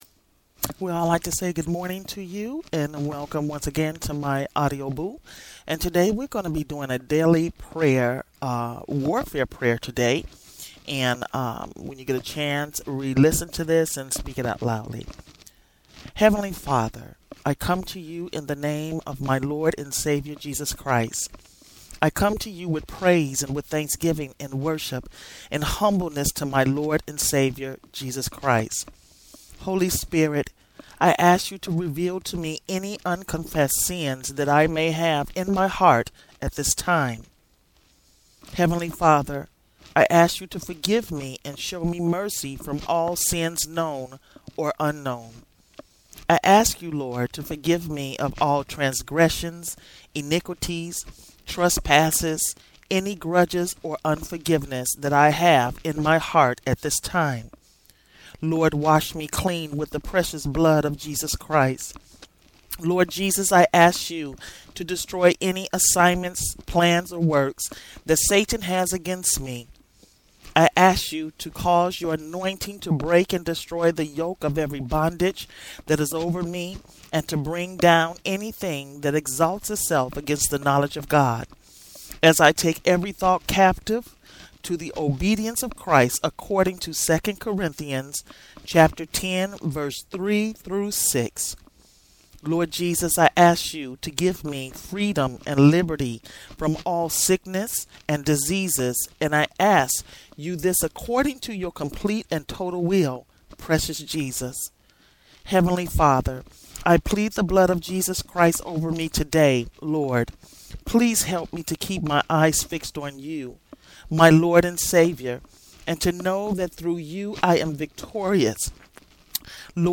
Daily Warfare Prayer
This is a daily warfare prayer.